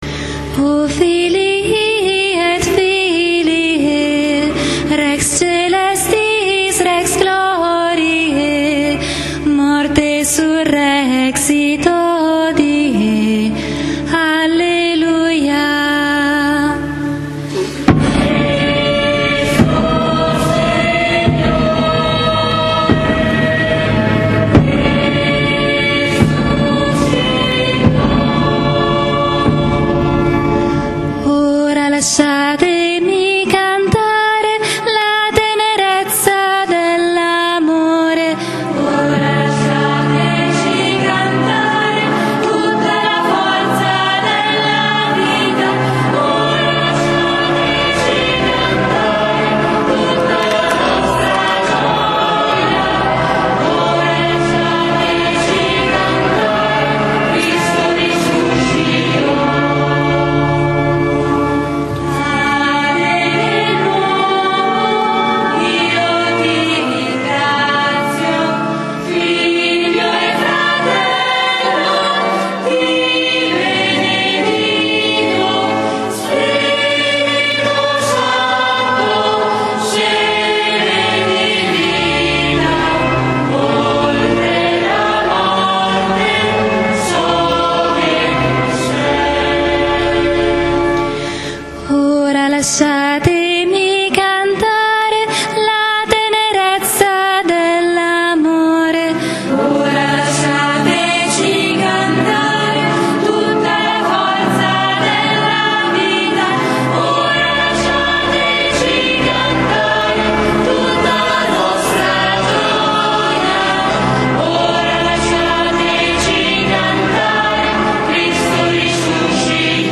PASQUA DI RESURREZIONE
canto: Ora lasciateci cantare